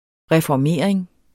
Udtale [ ʁεfɒˈmeˀɐ̯eŋ ]